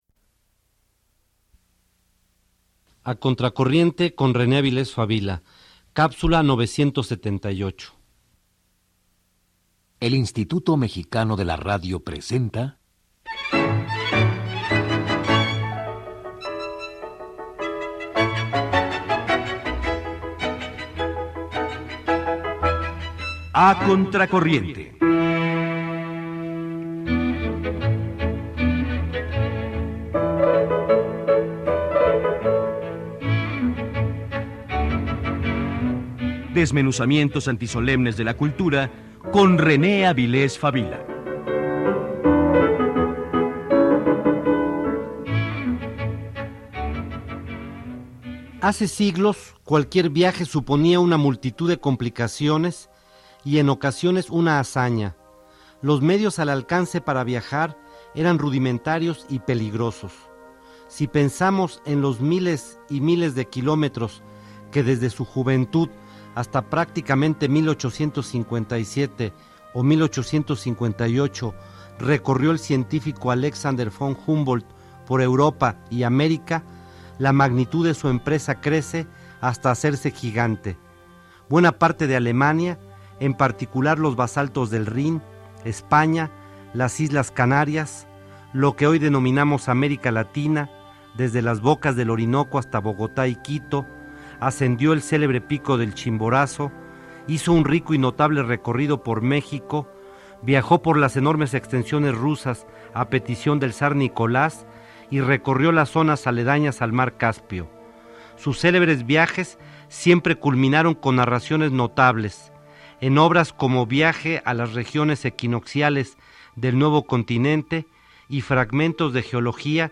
Escucha un breve comentario sobre Humboldt en el programa de René Avilés Fabila “A contra corriente”, transmitido en 1997.